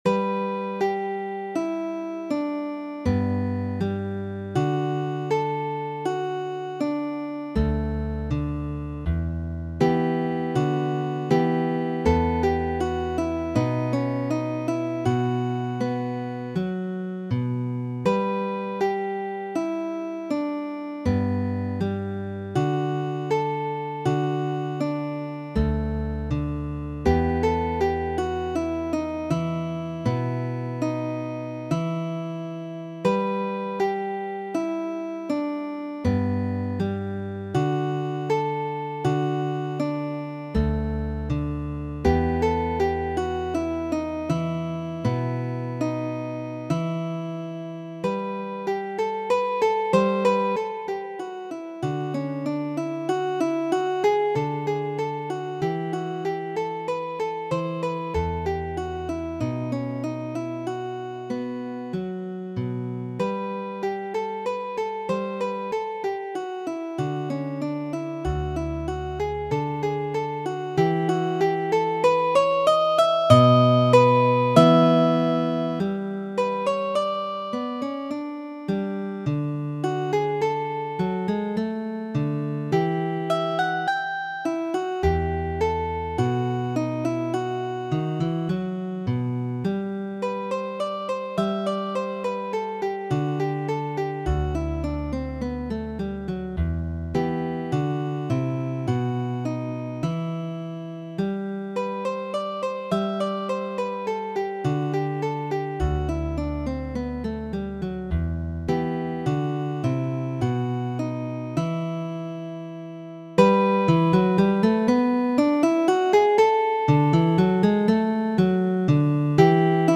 Konservu tiujn bovinojn por mi , aro da variajxoj verkitaj de Ludoviko Narvaez en la 16-a jarcento por viuxelo. Midigitaj de mi.